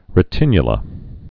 (rĭ-tĭnyə-lə)